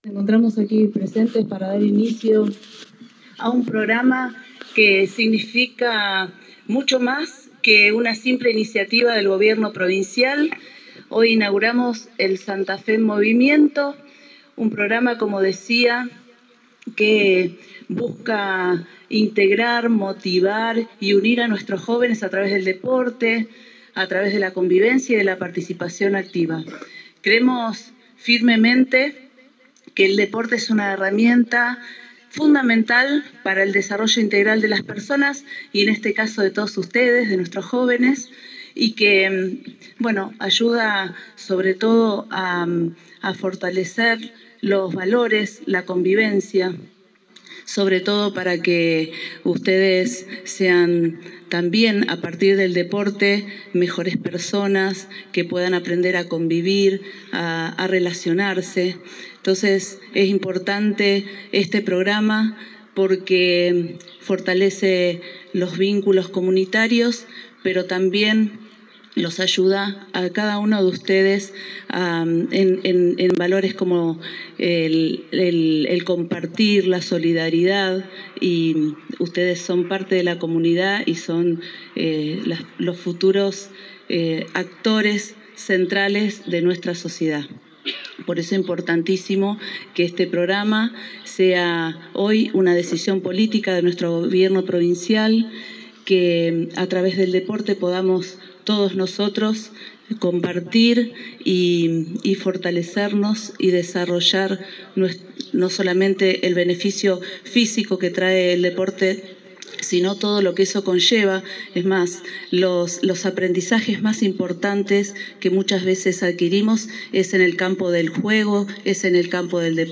El acto de apertura tuvo lugar en el polideportivo de la Ciudad de Vera para la instancia local de las distintas diciplinas deportivas que contempla el programa.
Paula Mitre – Intendente de la Ciudad de Vera